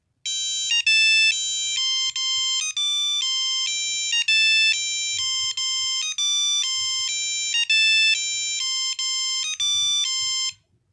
Motorola c113